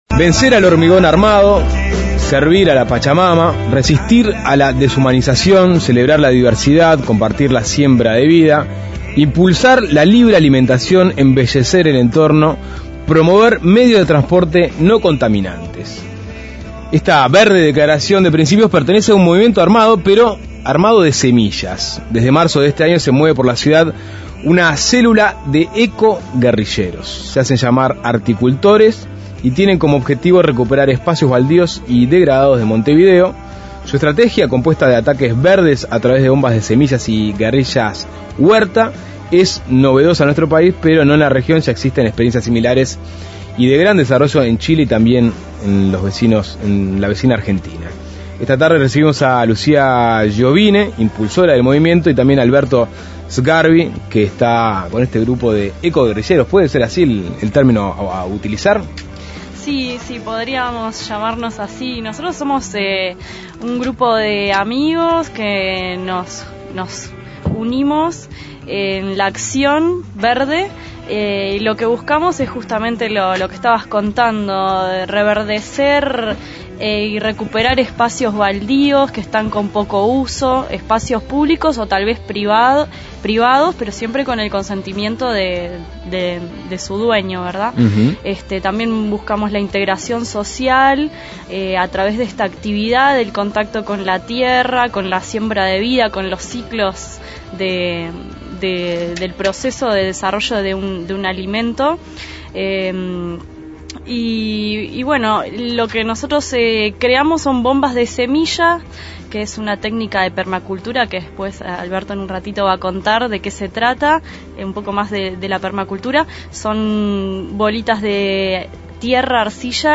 Entrevista con los Articultores montevideanos